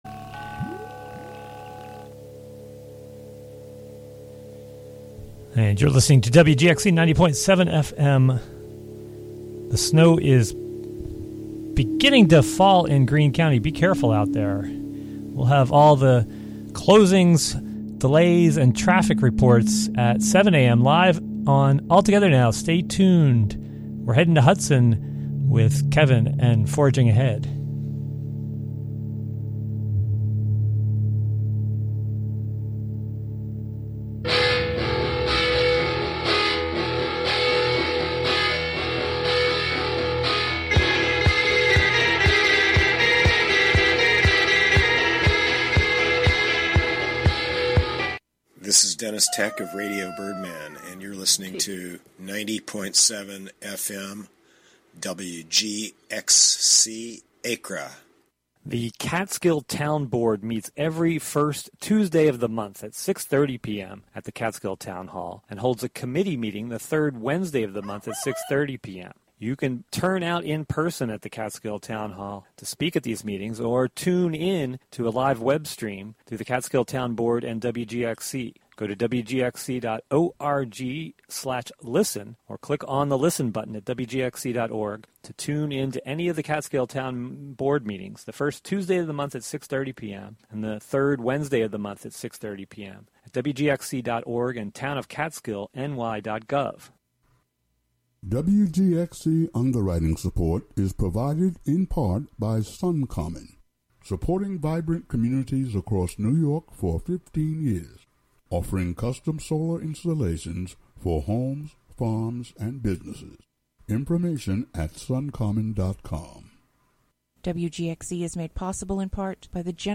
Monthly program featuring music and interviews from Dutchess County resident broadcast live from WGXC's Hudson studio.